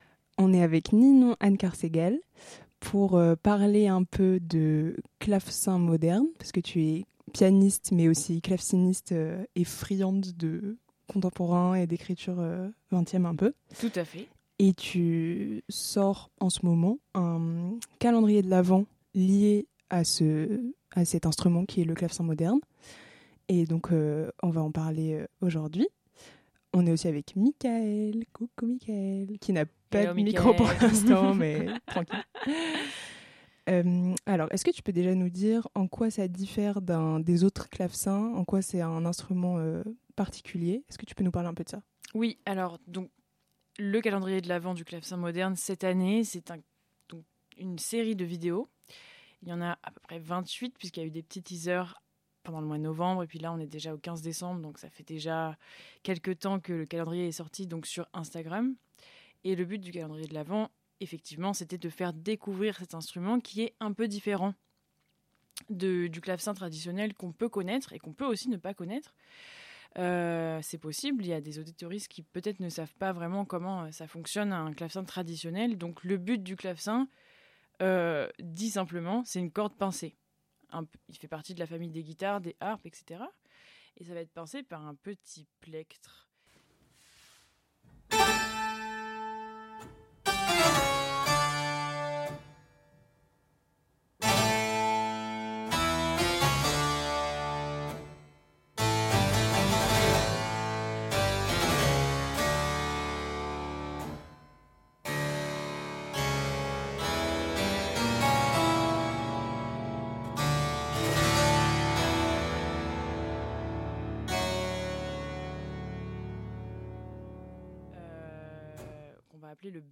Calendrier de l'avent/Clavecin
Classique & jazz